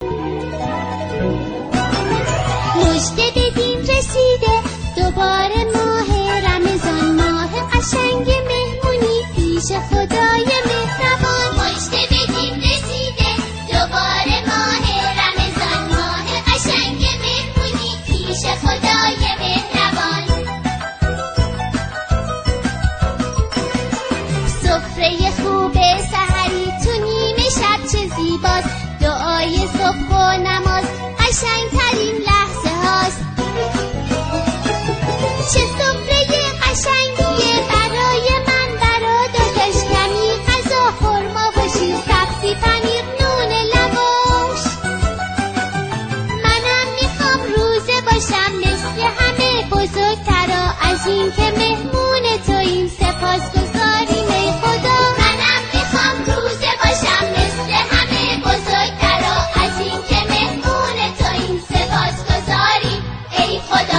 آهنگ کودکانه ماه رمضان